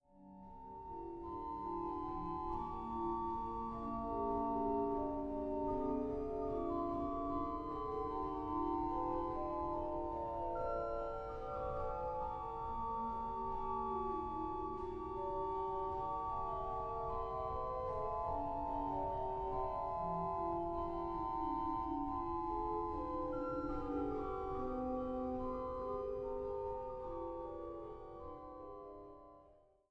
Walcker-Orgel